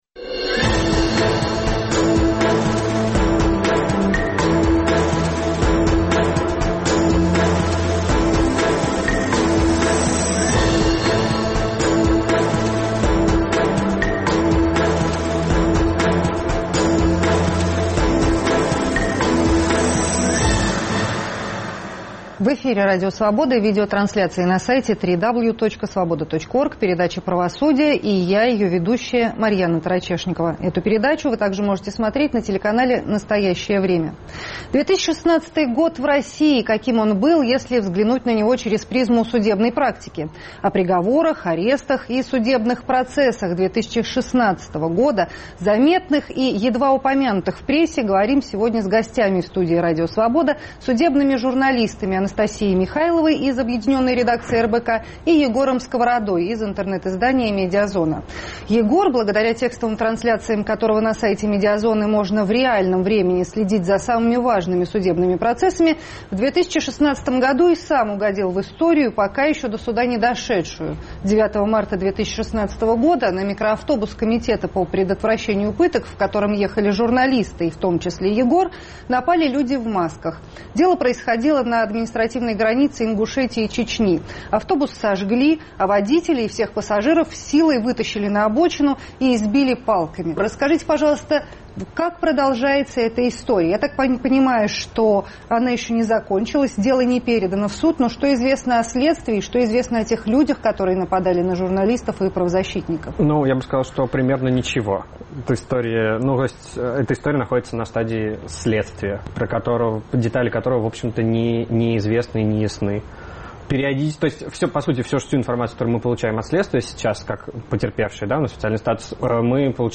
2016 год в России – каким он был, если взглянуть на него через призму судебной практики? О приговорах, арестах и судебных процессах 2016 года, заметных и едва упомянутых в прессе, говорим сегодня с гостями в студии Радио Свобода, судебными журналистами